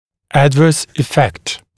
[‘ædvɜːs ɪ’fekt] [æd’vɜːs][‘эдвё:с и’фэкт] [эд’вё:с]неблагоприятный эффект, результат, отрицательное воздействие, отрицательное влияние, неблагоприятное воздействие, неблагоприятное влияние